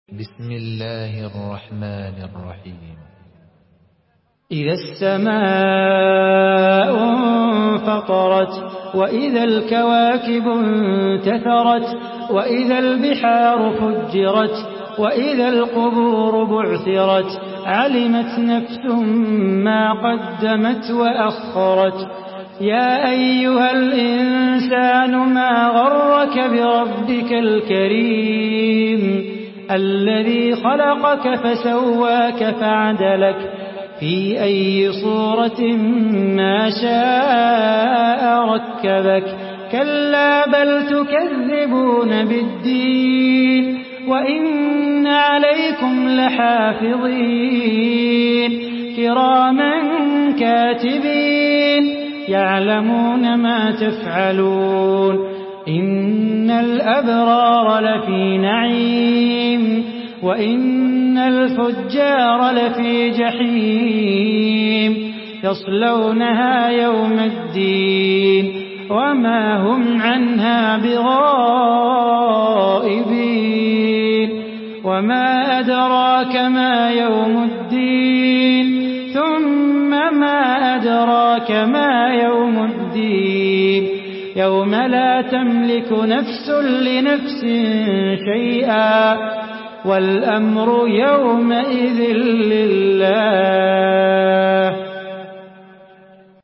Surah Infitar MP3 by Salah Bukhatir in Hafs An Asim narration.
Murattal